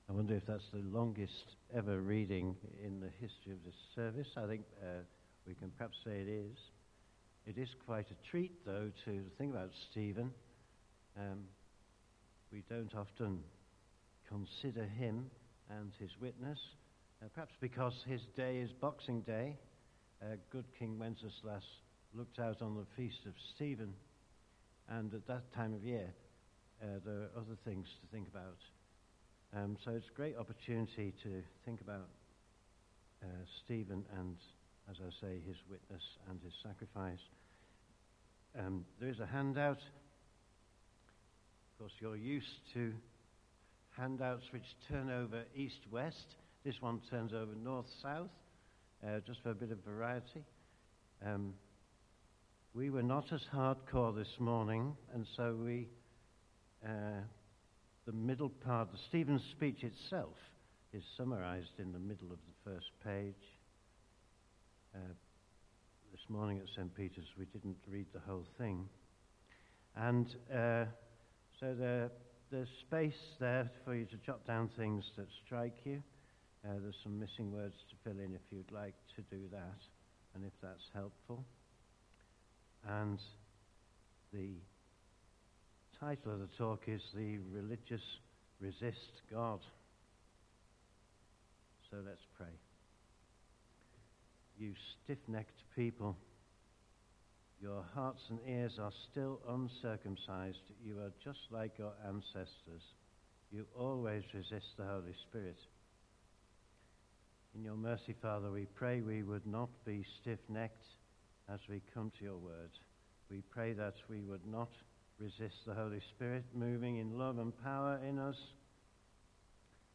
Media Library The Sunday Sermons are generally recorded each week at St Mark's Community Church.
Theme: The religious resist God Sermon